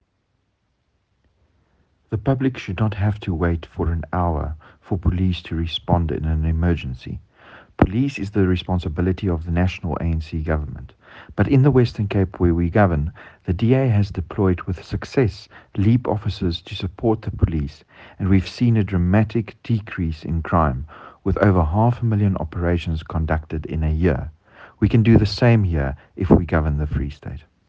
Afrikaans soundbites by George Michalakis MP.